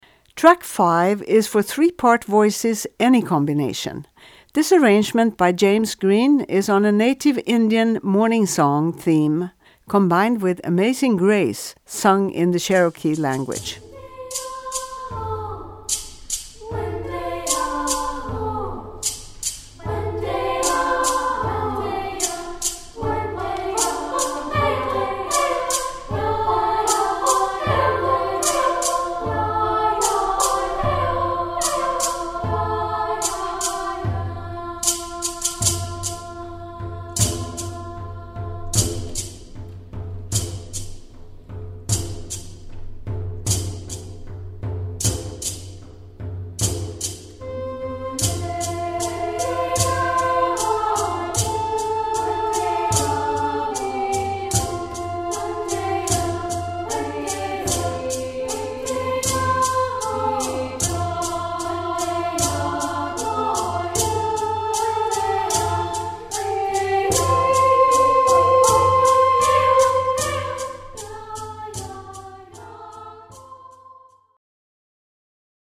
Voicing: 3-Part